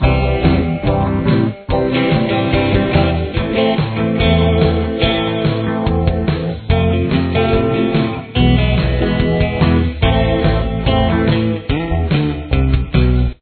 Tempo: 143 beats per minute
Key Signature: A minor